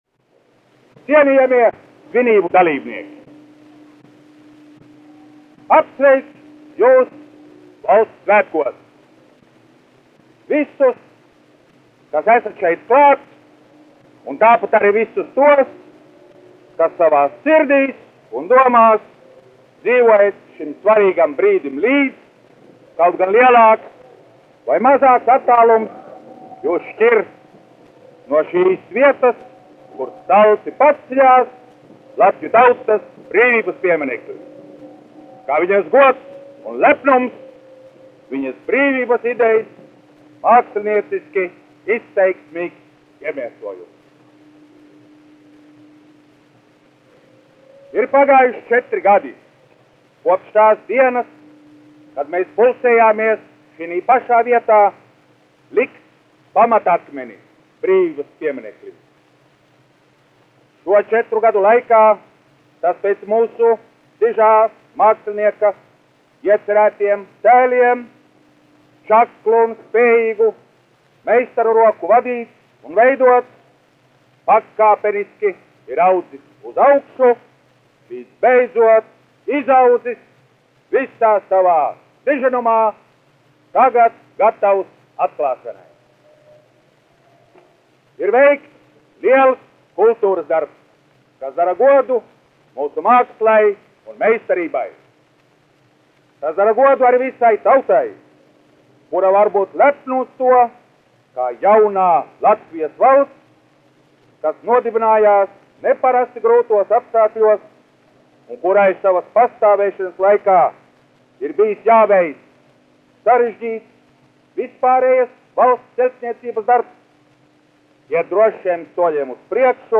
Brīvības pieminekļa atklāšana : valsts prezidenta Alberta Kvieša runa 1935.g. 18.novembrī | LNB Digitālā bibliotēka - DOM PIEEJA